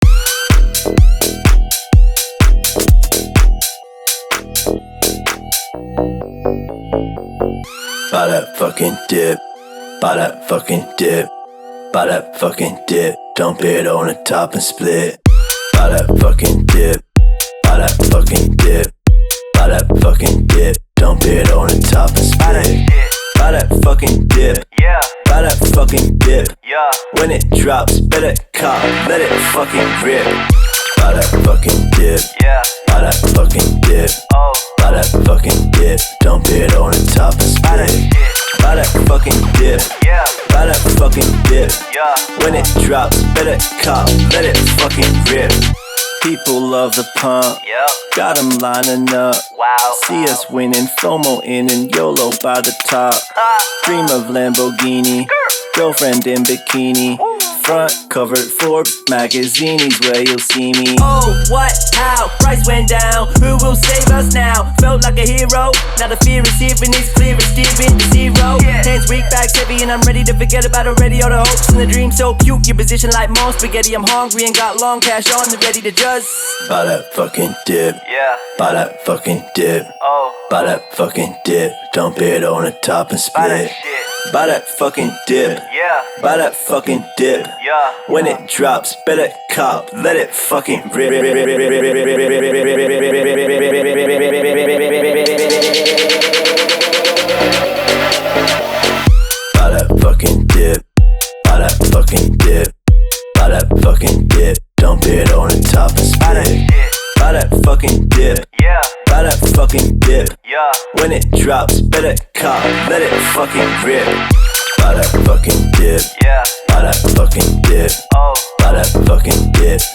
Crypto house remix.